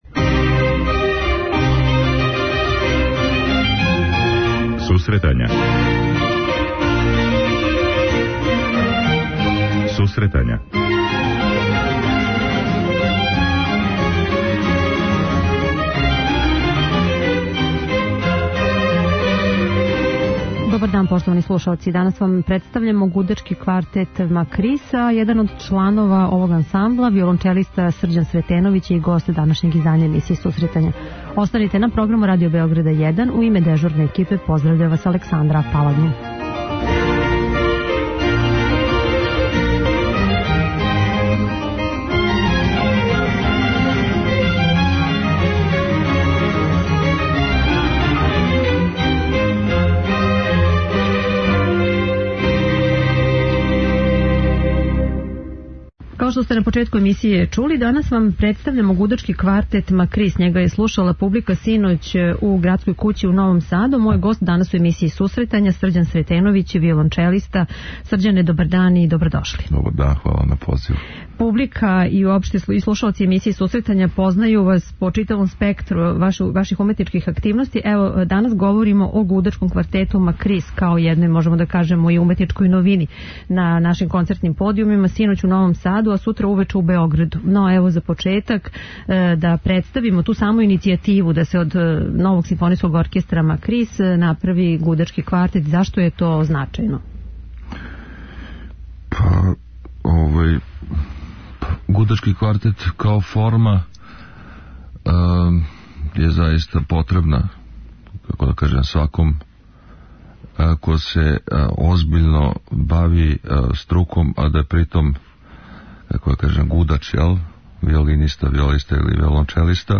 преузми : 10.79 MB Сусретања Autor: Музичка редакција Емисија за оне који воле уметничку музику.